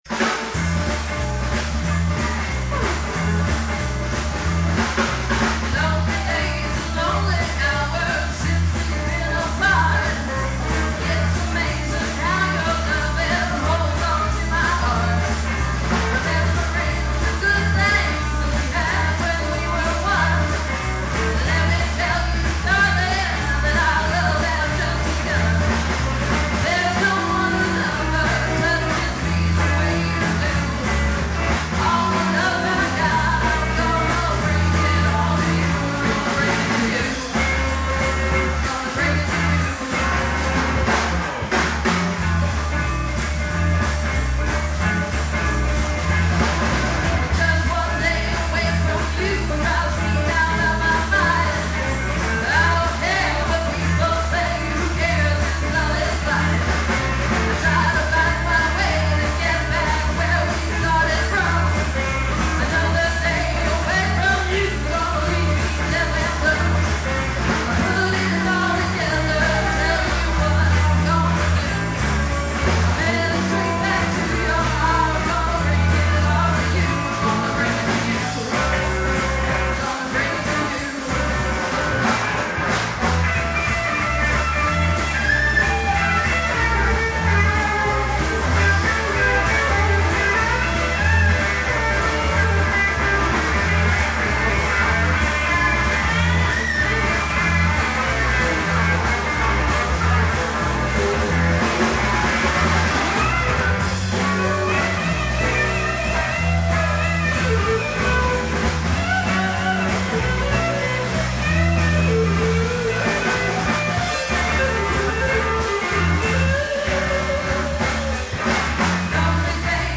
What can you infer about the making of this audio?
Final Farewell - Cafe L.A.